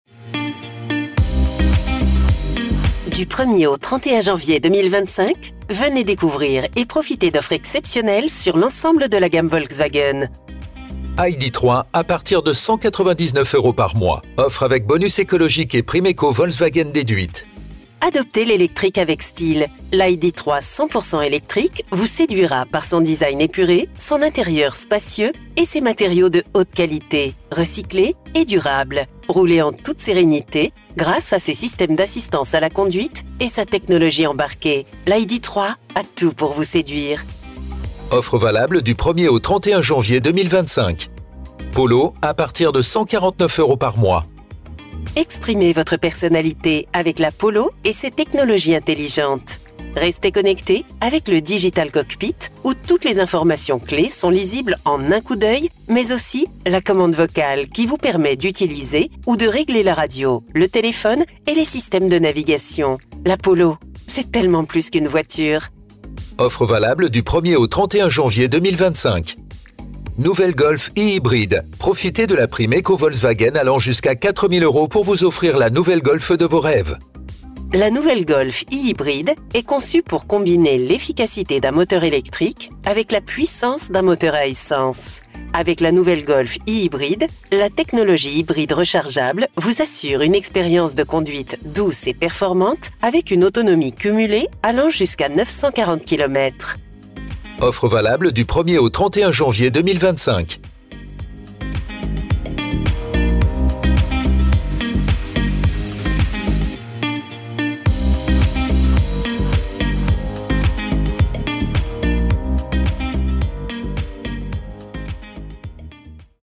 Accueil téléphonique
L’enregistrement de vos messages est réalisé par des professionnels dans notre studio d’enregistrement.